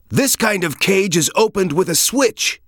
File File history File usage Metadata All04_a09_kn.ogg  (Ogg Vorbis sound file, length 2.7 s, 277 kbps) This file is an audio rip from a(n) Xbox 360 game.